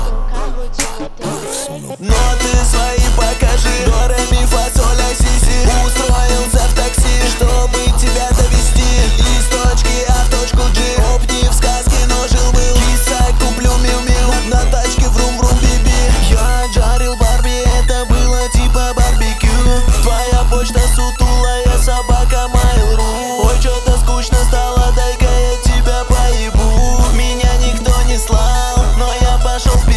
Жанр: Хип-Хоп / Рэп / Русский рэп / Русские
Hip-Hop, Rap